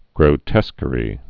(grō-tĕskə-rē)